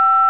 Tone5
TONE5.WAV